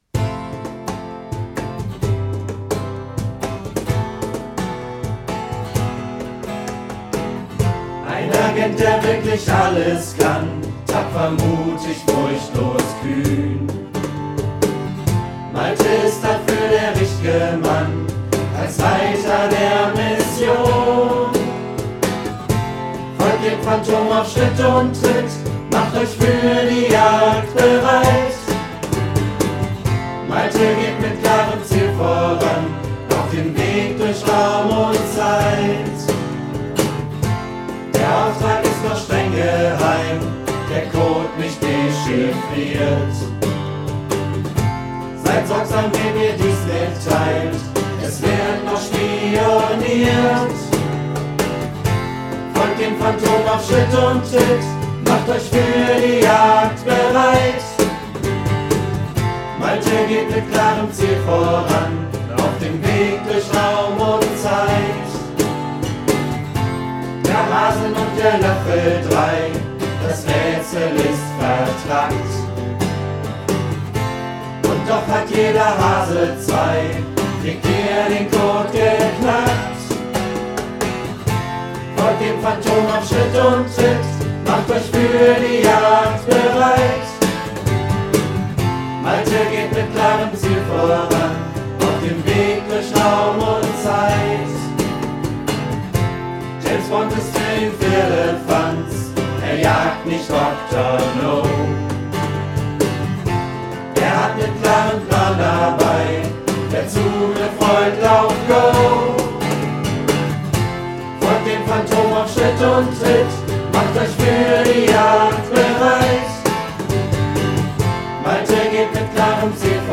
Brecht auf (D-Dur)